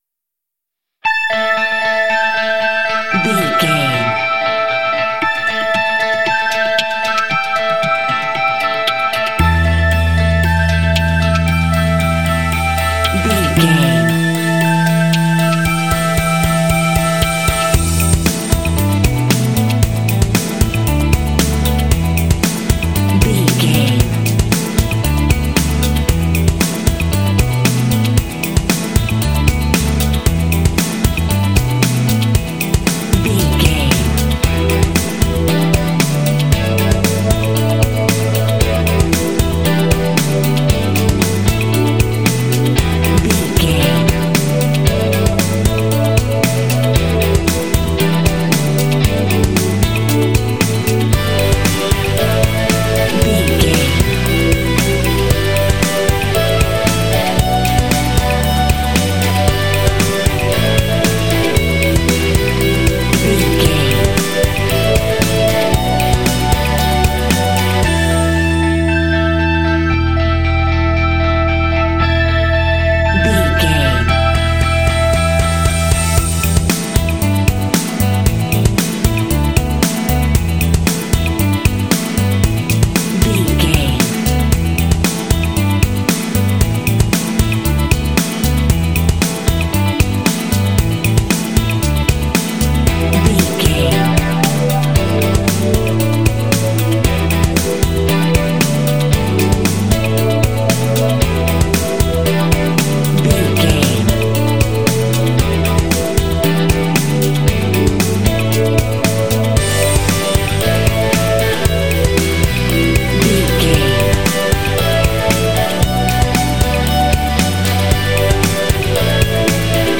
Dorian
groovy
bright
electric guitar
strings
drums
bass guitar
synthesiser
percussion
symphonic rock